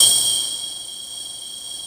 45 METAL  -R.wav